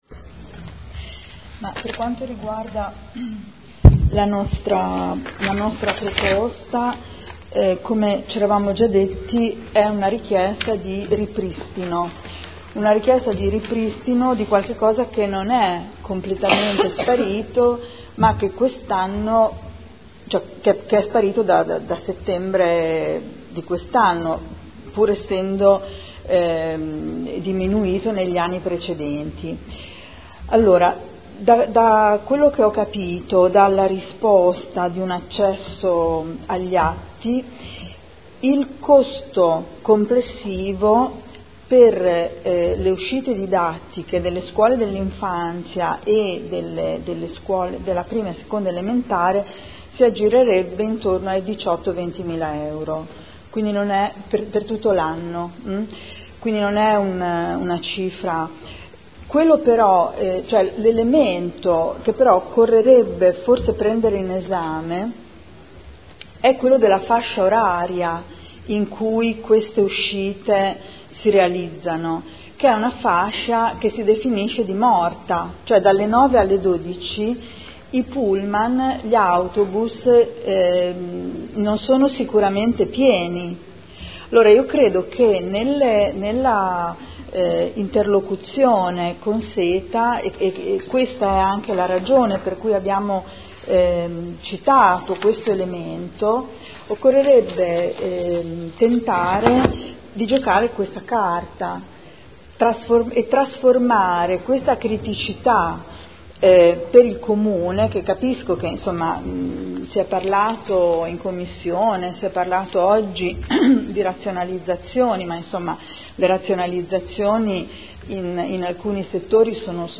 Seduta del 09/02/2015. Dibattito sugli ordini del giorno inerenti il trasporto scolastico gratuito o agevolato